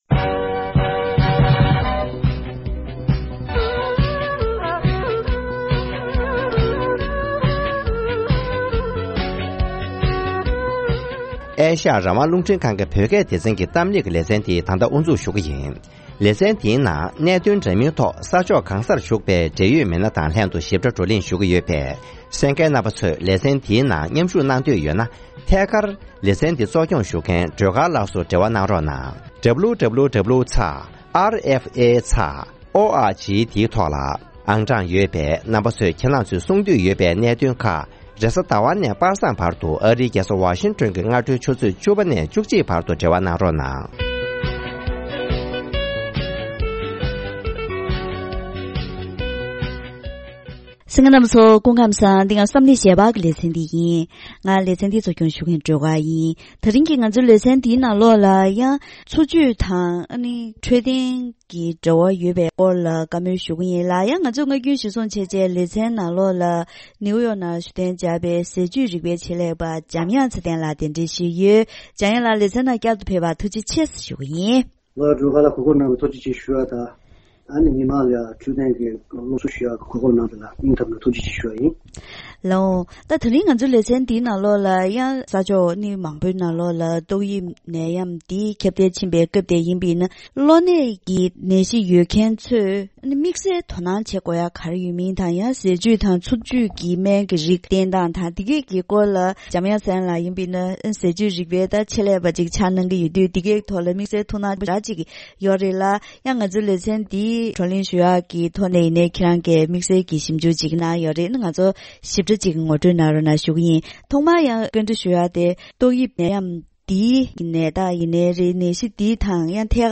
༄༅།།དེ་རིང་གི་གཏམ་གླེང་ཞལ་པར་ལེ་ཚན